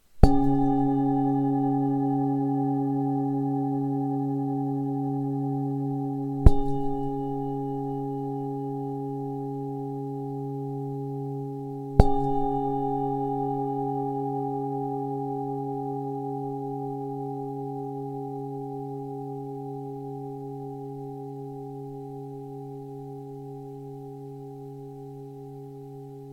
Zdobená tibetská mísa C3 22,5cm
Nahrávka mísy úderovou paličkou:
Jde o ručně tepanou tibetskou zpívající mísu dovezenou z Nepálu.